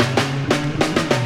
Band Fill.wav